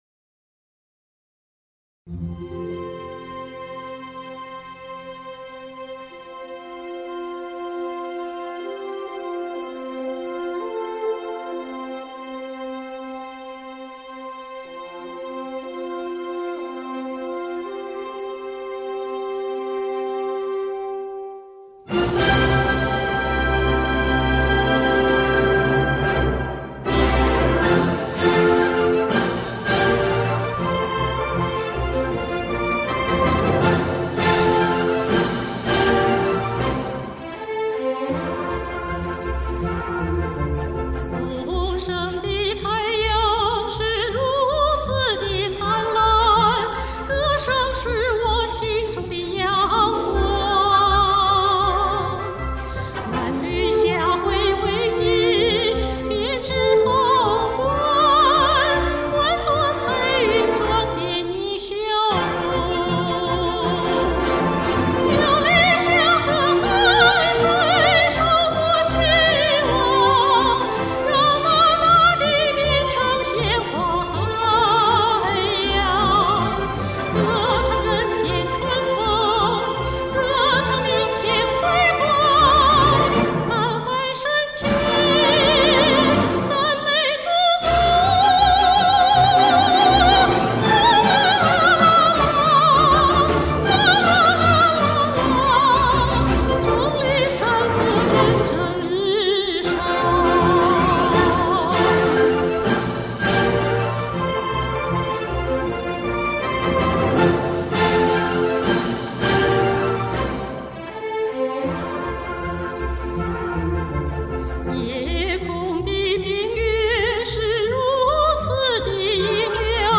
“十一”国庆节了，这里没有黄金周，就唱首欢快些的歌颂祖国的歌曲吧。